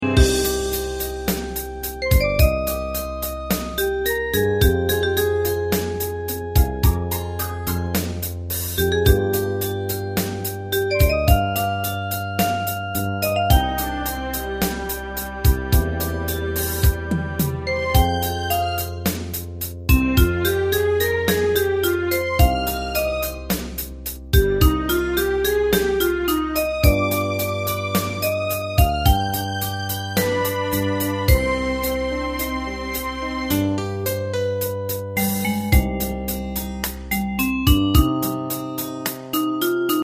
大正琴の「楽譜、練習用の音」データのセットをダウンロードで『すぐに』お届け！
Unison musical score and practice for data.